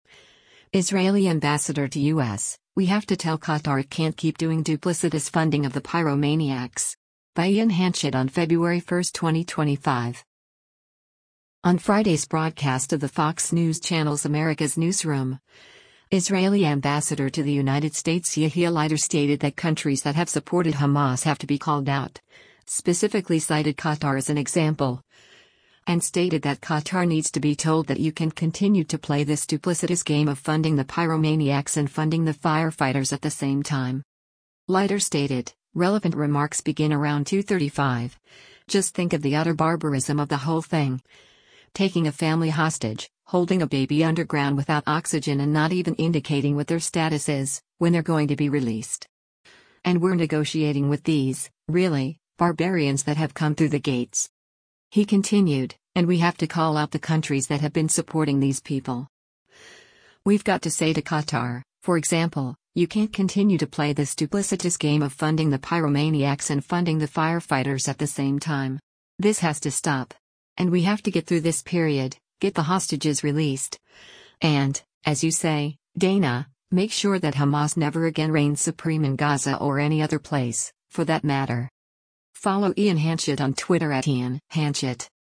On Friday’s broadcast of the Fox News Channel’s “America’s Newsroom,” Israeli Ambassador to the United States Yechiel Leiter stated that countries that have supported Hamas have to be called out, specifically cited Qatar as an example, and stated that Qatar needs to be told that “you can’t continue to play this duplicitous game of funding the pyromaniacs and funding the firefighters at the same time.”